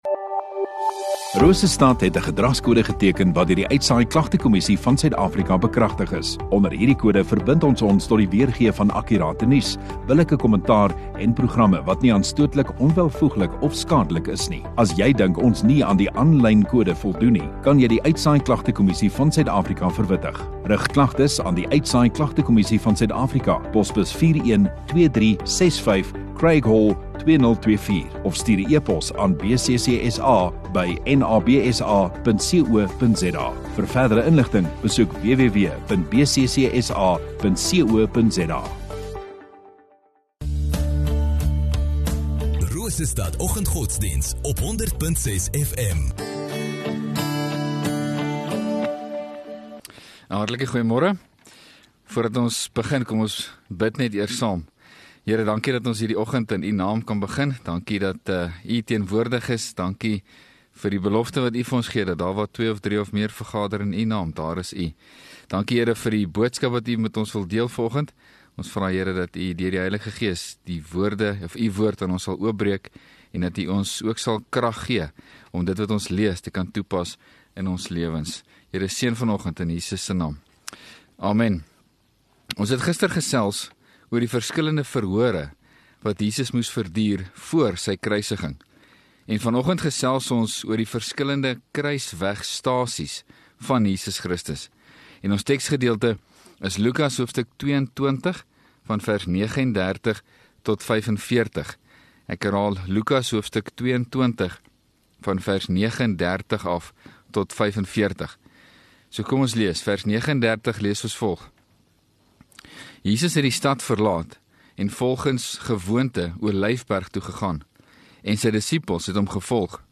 3 Mar Dinsdag Oggenddiens